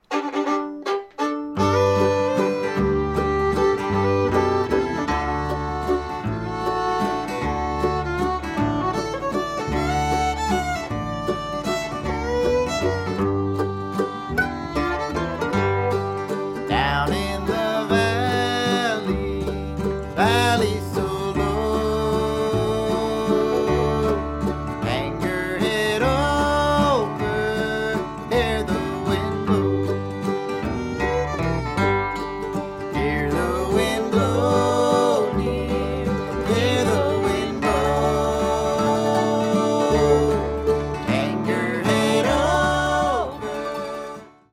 Band version (key of G)